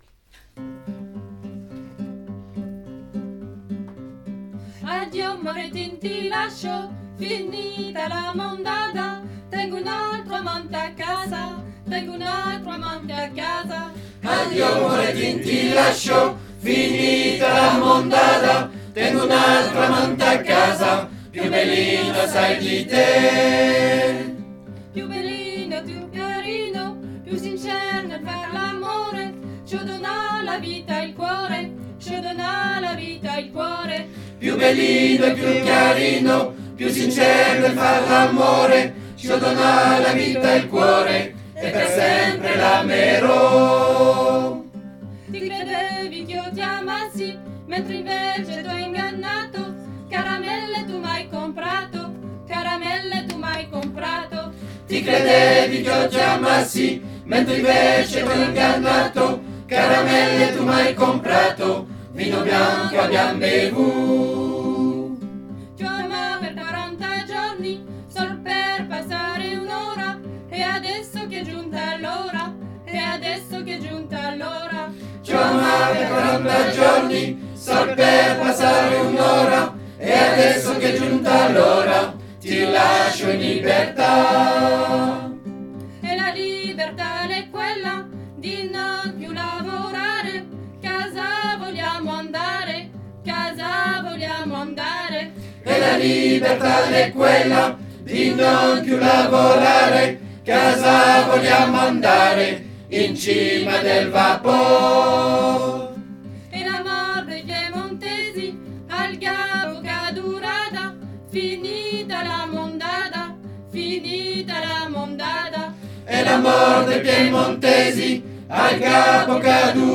Addio_Morettin_Ensemble.mp3